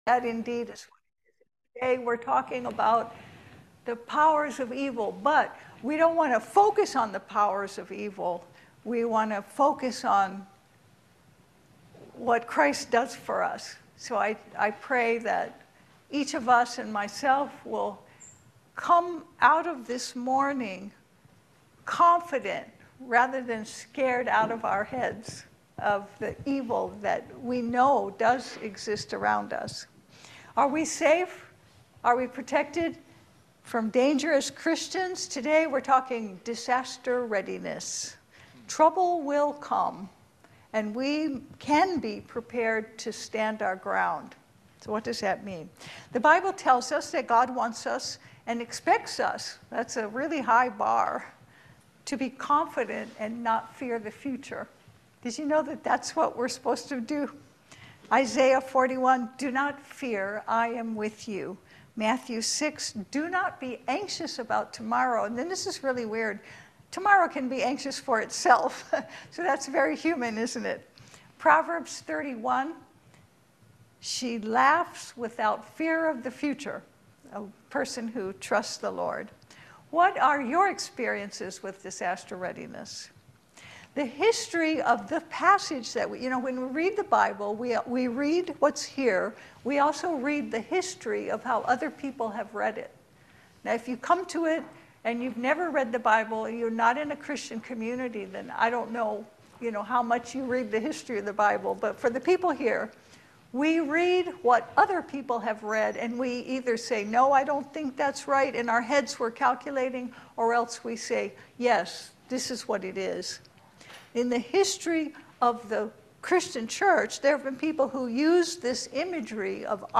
Passage: Ephesians 6:10-20 Service Type: Sunday Service Finally